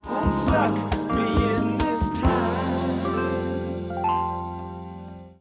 all type I